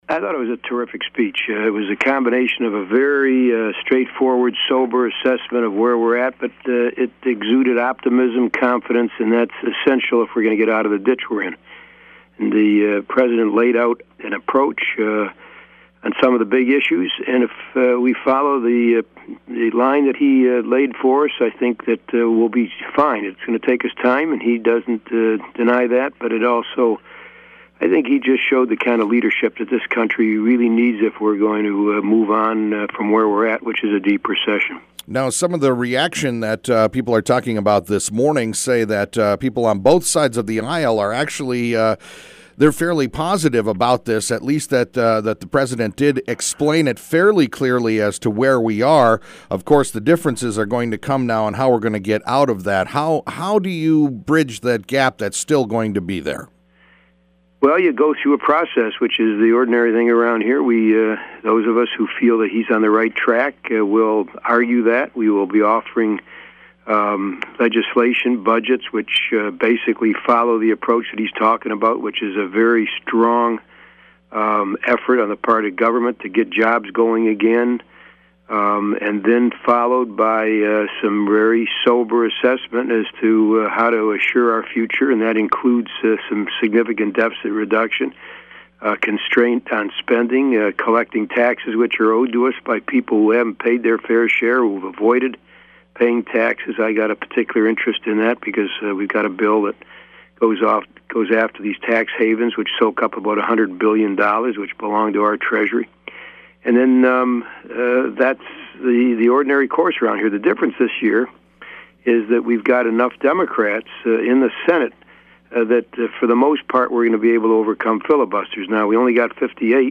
LEVINDiscussion with the Senator about his reactions an thoughts on the economic speech delivered by President Barack Obama last night in Washington, D.C.